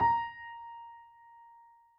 Added more instrument wavs
piano_070.wav